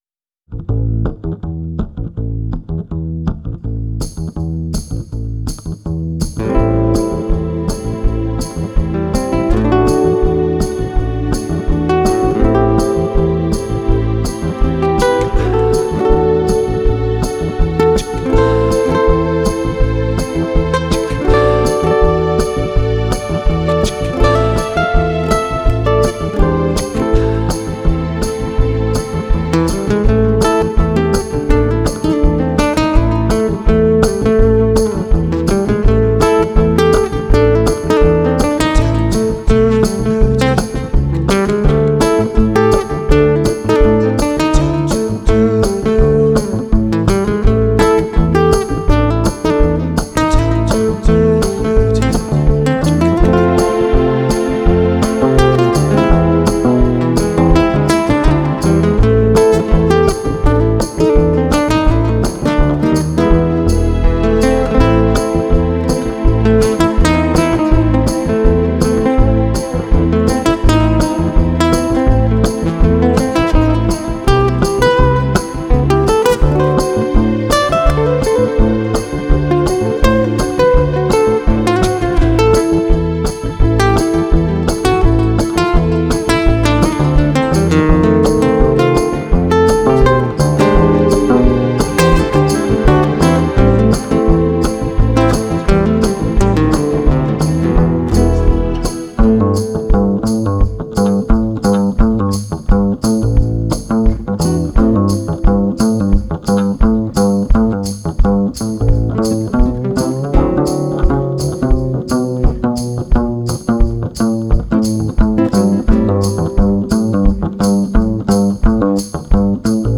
live instrumental album
GK Guitar
Harmonicas
Flute
Percussion